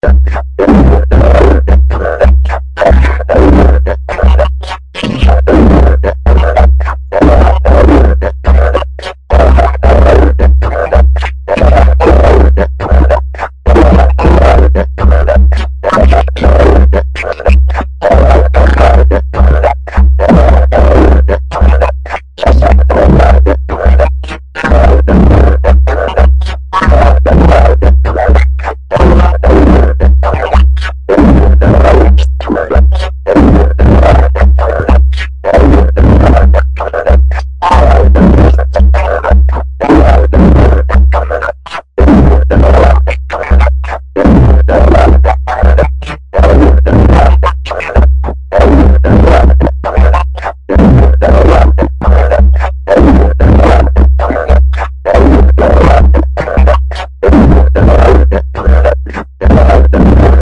噪声 " 噪声2 - 声音 - 淘声网 - 免费音效素材资源|视频游戏配乐下载
人声（约6.秒的树桩）录入Kaoss Pad.麦克风。老式的索尼，在任何地方都看不出是什么类型。4个垫子现场播放，过滤到Cubase中。在这个过程中进一步加工。地点：冰岛Reykjanesbær.